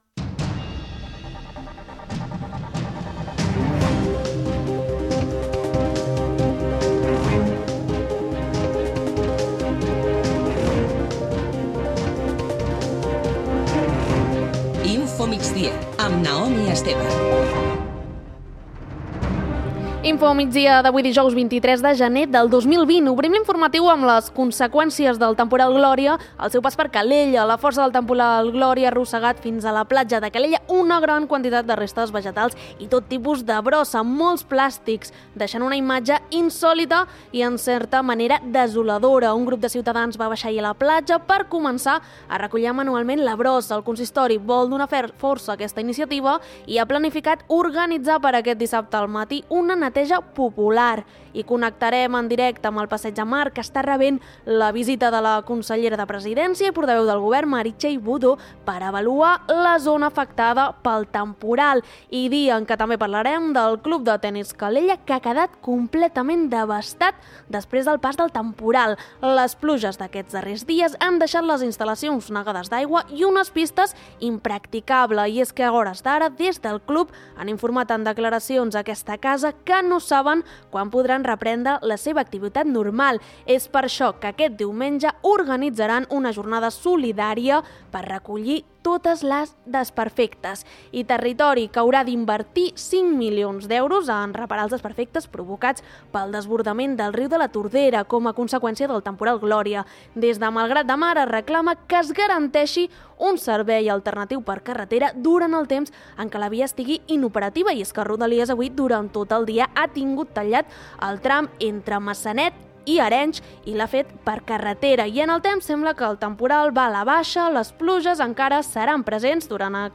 Notícies d’actualitat local i comarcal.